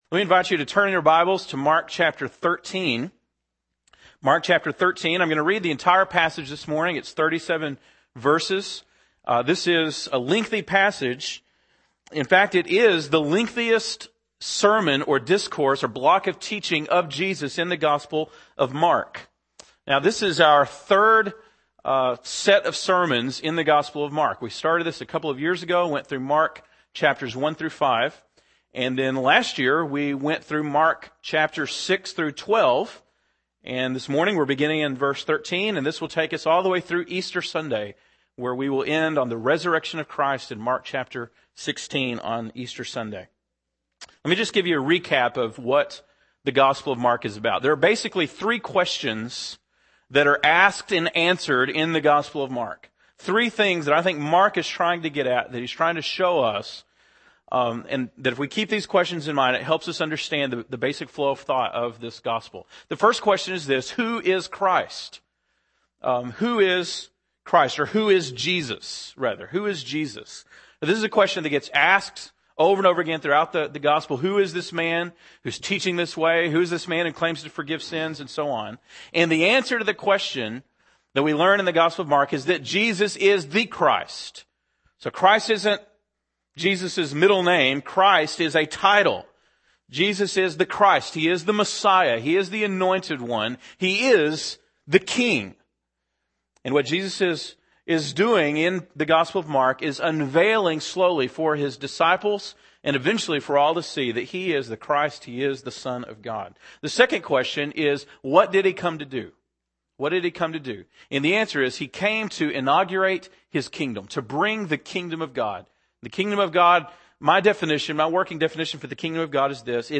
February 8, 2009 (Sunday Morning)